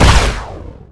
fire_photon2.wav